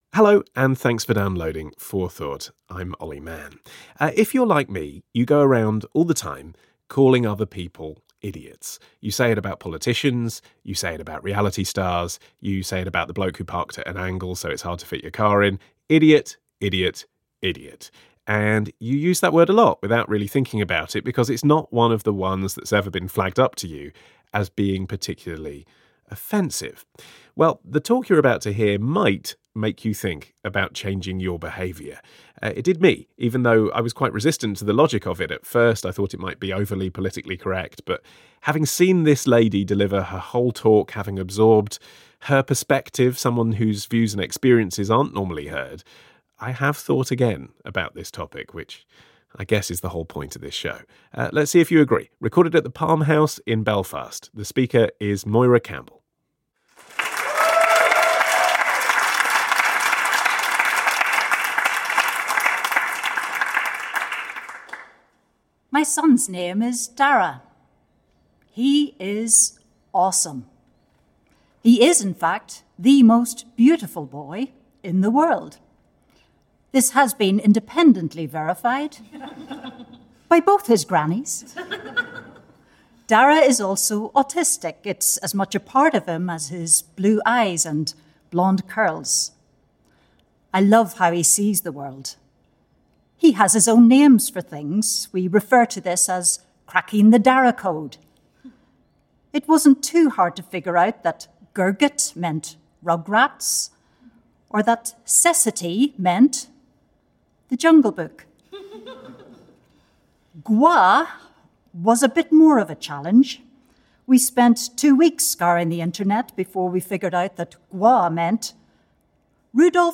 I was honoured to be invited to speak at a live recording for BBC Radio 4’s Four Thought programme in May on the theme of ‘Thinking Differently about Difference’. An edited version aired on June 6, 2018 and this is the link to the full podcast version, including Q&A.